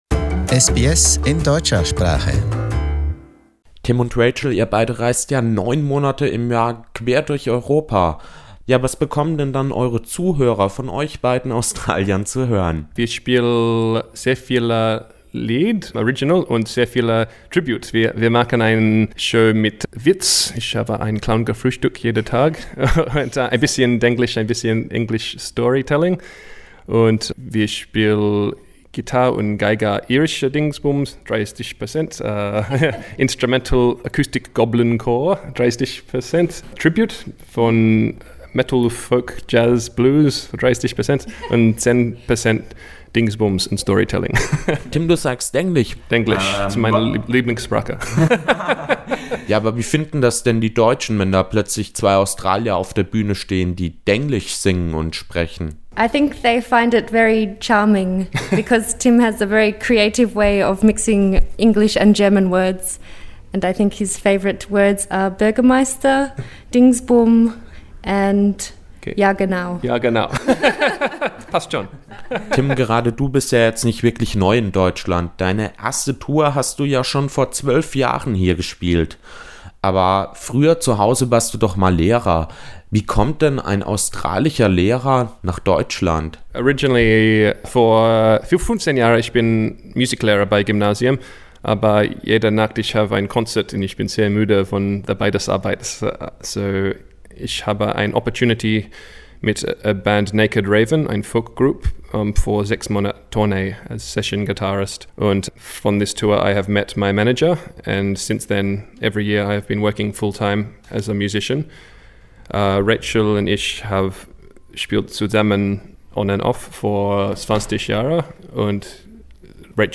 In winter, they flee home to Australia. We met up with the adventurous duo at a concert in Nuremberg.